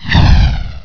1 channel
spell.wav